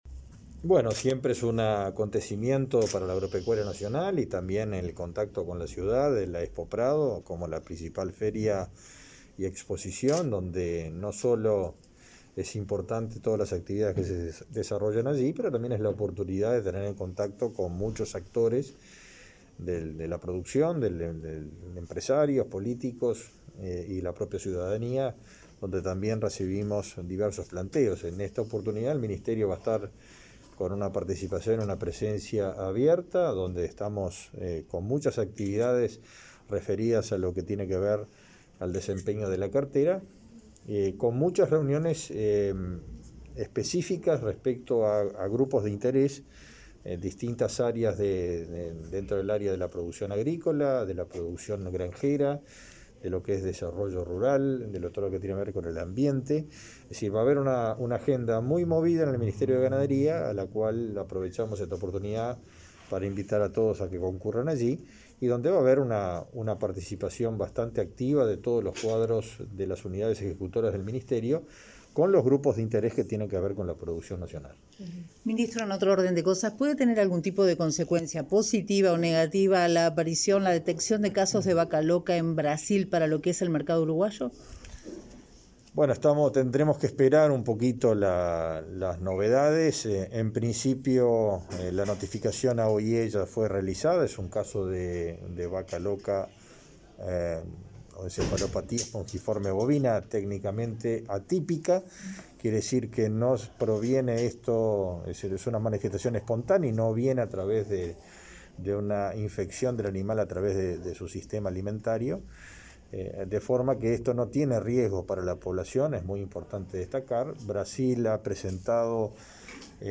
Declaraciones a la prensa del ministro de Ganadería, Fernando Mattos